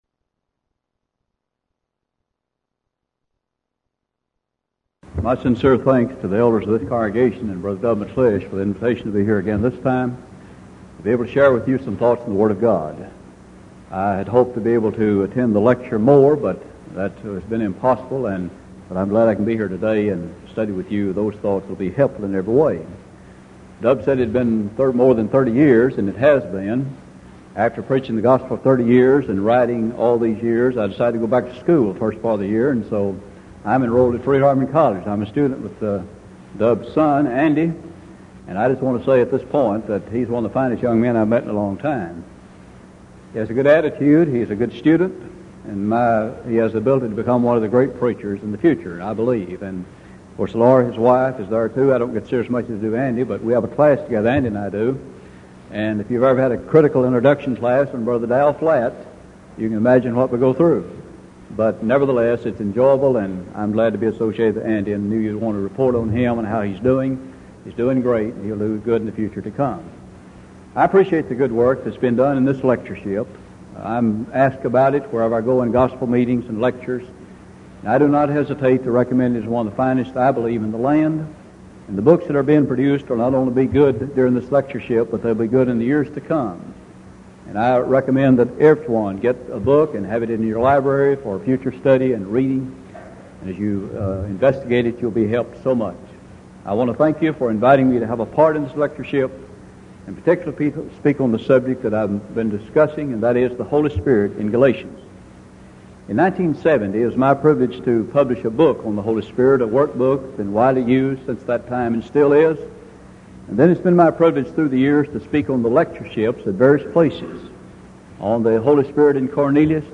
Event: 1986 Denton Lectures
lecture